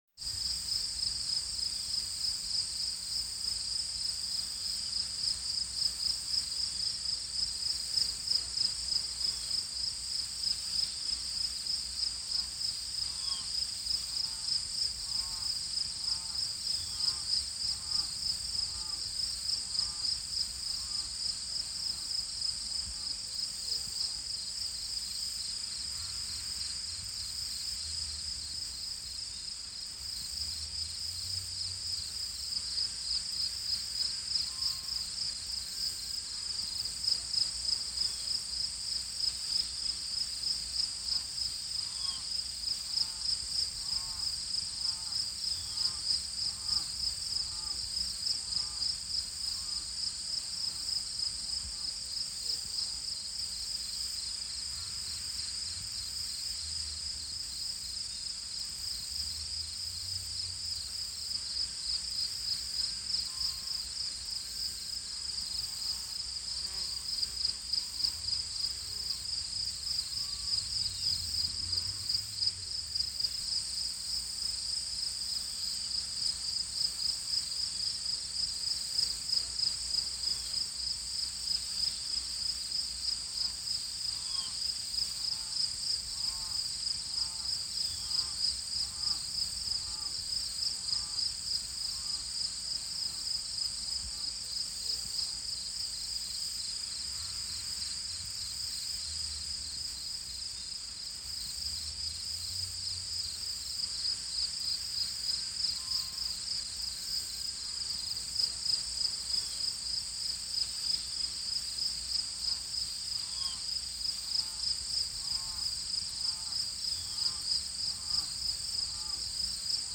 دانلود آهنگ جیرجیرک 4 از افکت صوتی انسان و موجودات زنده
دانلود صدای جیرجیرک 4 از ساعد نیوز با لینک مستقیم و کیفیت بالا
جلوه های صوتی